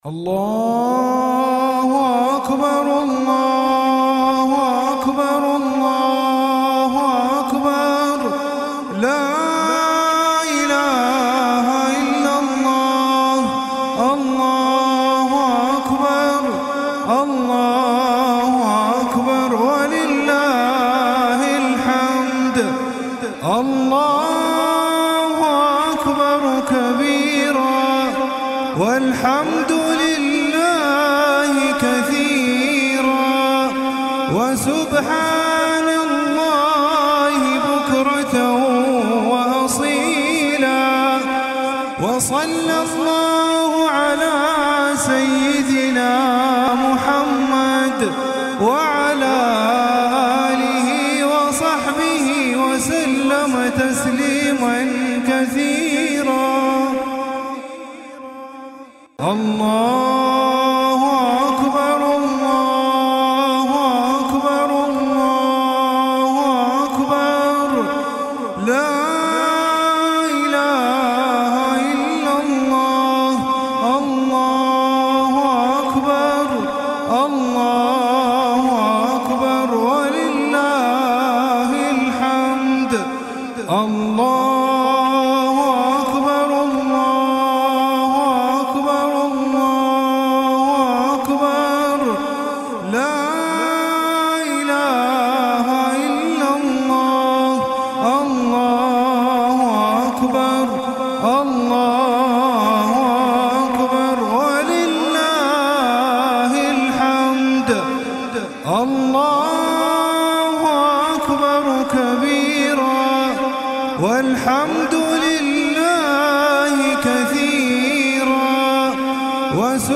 Zikr · Makkah Mukarrama
CategoryZikr
VenueMakkah Mukarrama
Event / TimeAfter Fajr Prayer